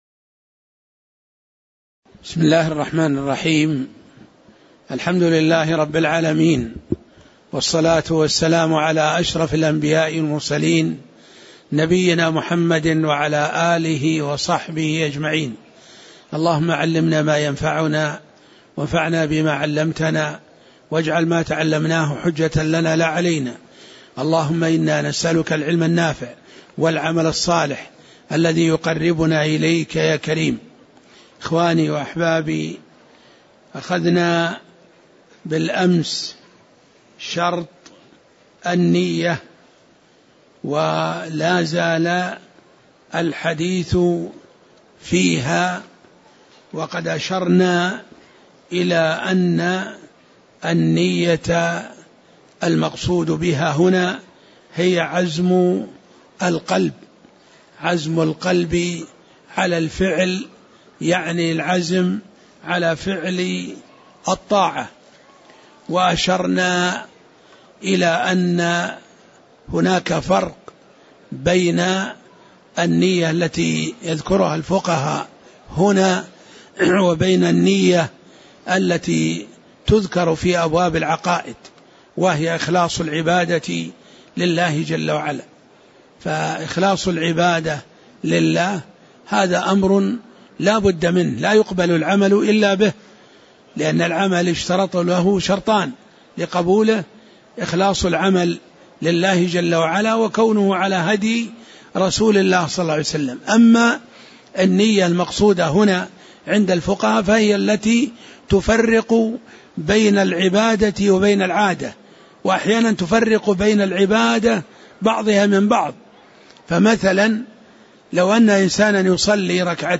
تاريخ النشر ٤ جمادى الأولى ١٤٣٨ هـ المكان: المسجد النبوي الشيخ